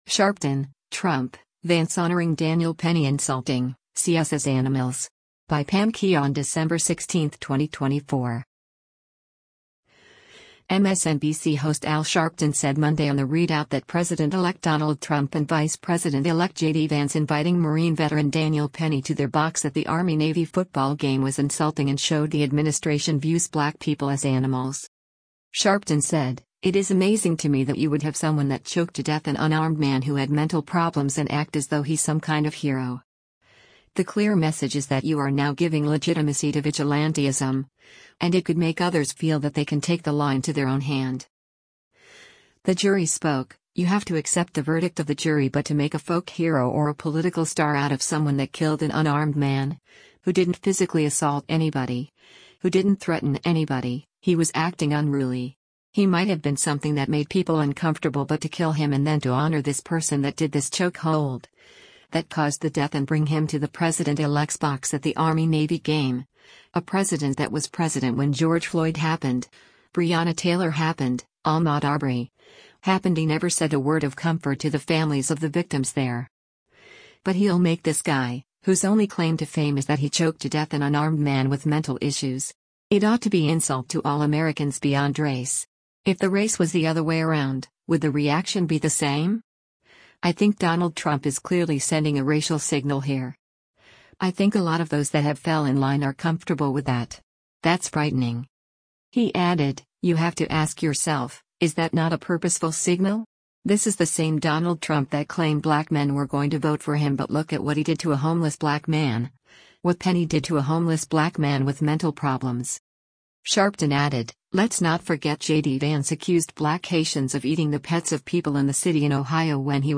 MSNBC host Al Sharpton said Monday on “The Reid Out” that President-elect Donald Trump and Vice President-elect JD Vance inviting Marine veteran Daniel Penny to their box at the Army-Navy football game was insulting and showed the administration views black people “as animals.”